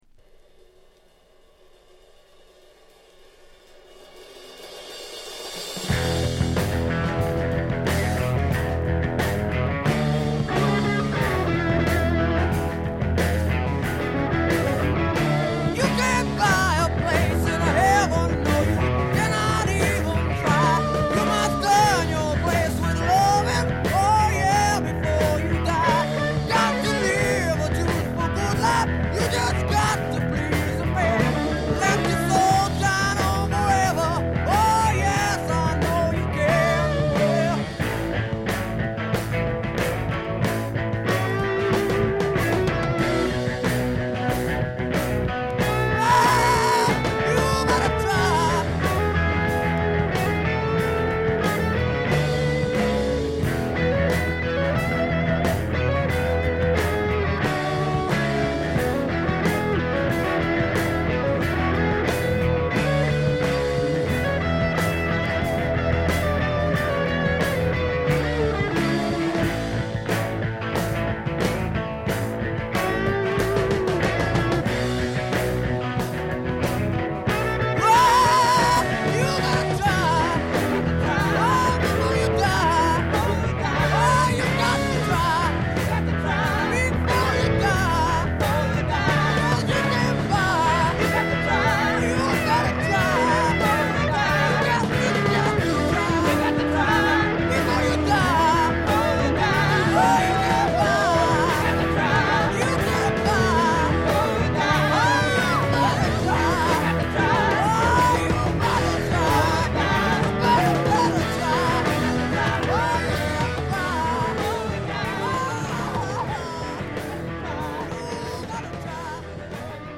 Just great guitar throughout and snappy drums too.